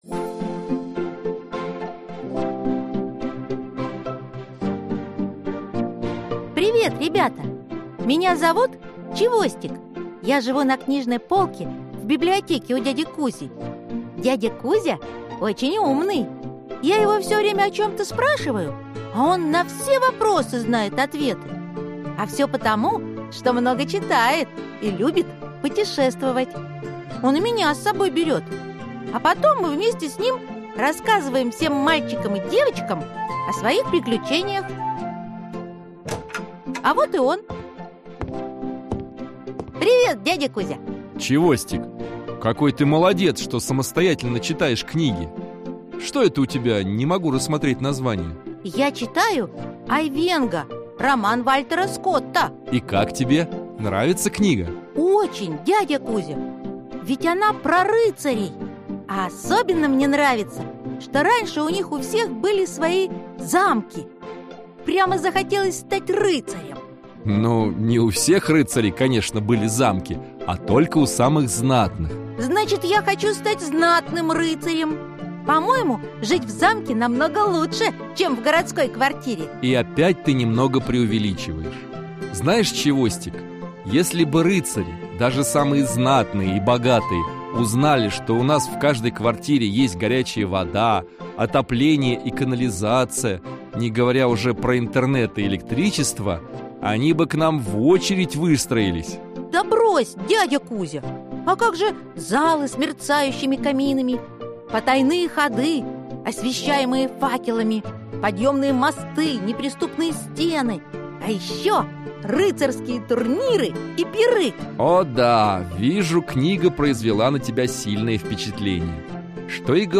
Аудиокнига Замки | Библиотека аудиокниг